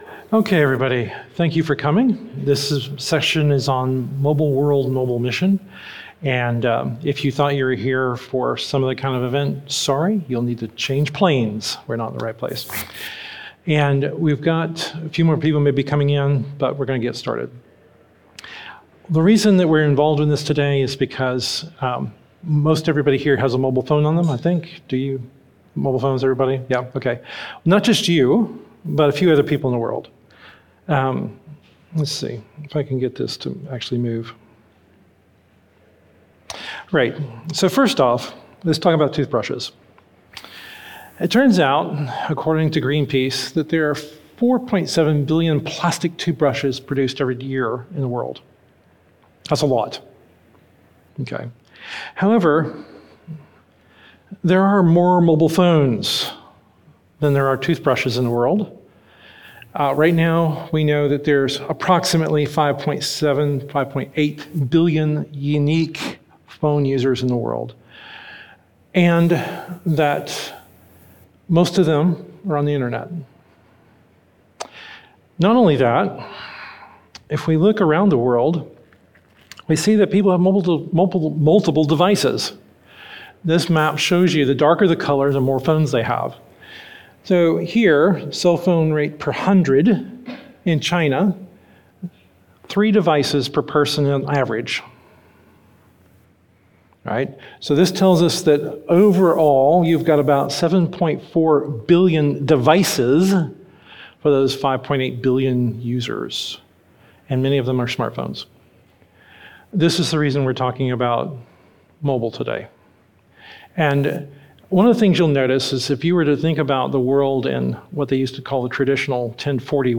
How can mobile technology be used to enable mission? A seminar from the 2025 Leaders' Conference.